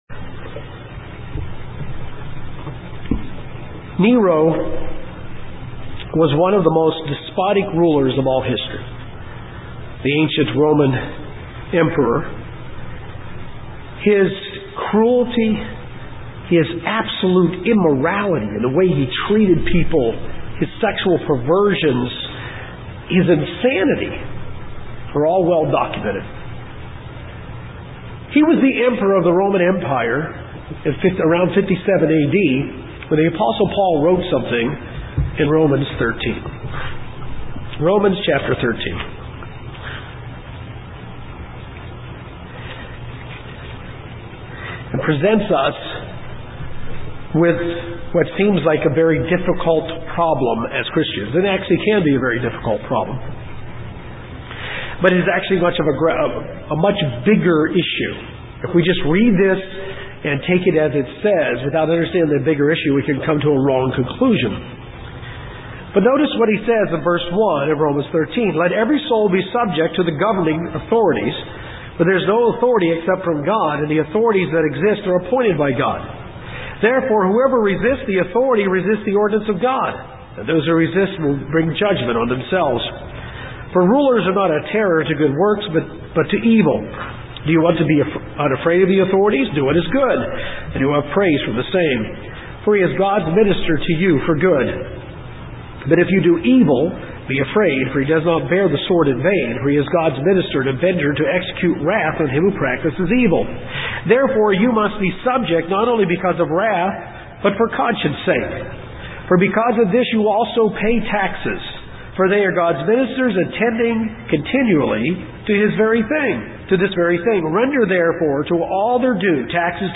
In this part of the sermon we will continue to look at the concept of authority. God has delegated authority to human governments. How are we to respond to civil authority?